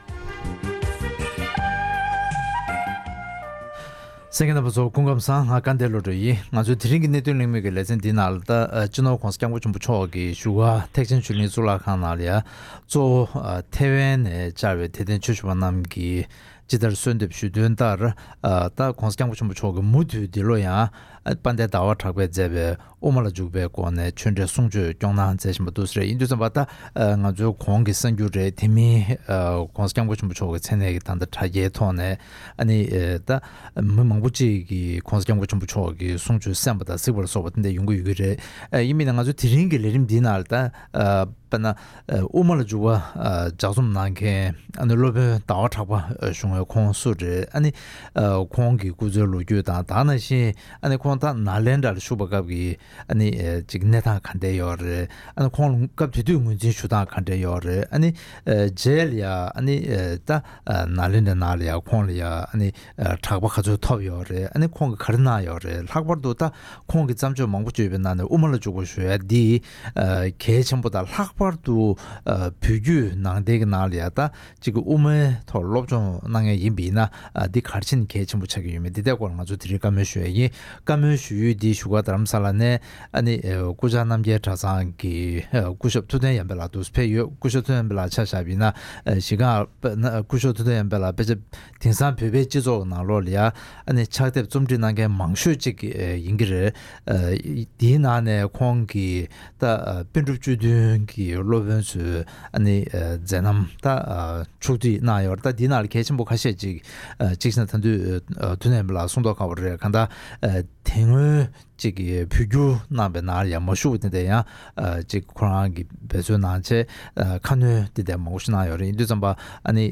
སློབ་དཔོན་ཟླ་བ་གྲགས་པའི་སྐུ་ཚེས་ལོ་རྒྱུས་དང་ཁོང་གི་བརྩམས་པའི་དབུ་མ་ལ་འཇུག་པའི་སྐོར་གླེང་མོལ།